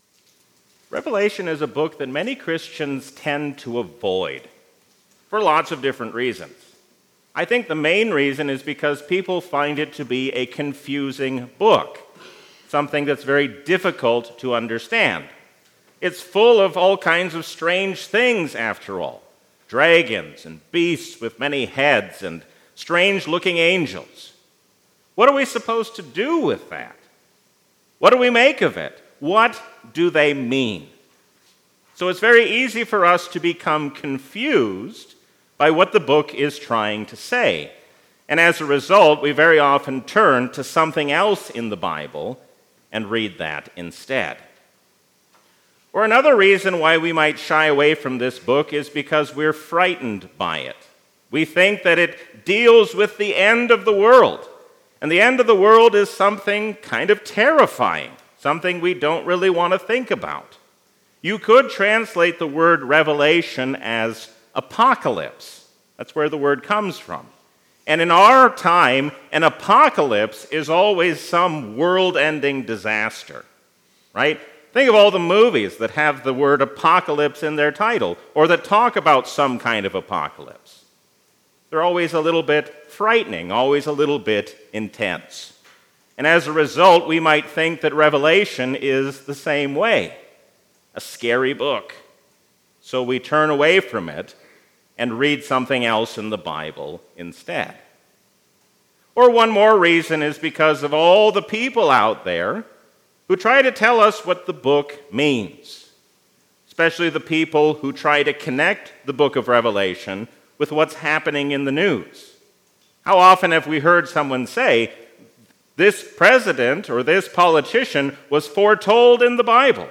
A sermon from the season "Easter 2023." Through truth without love is not good, love without truth is just as bad.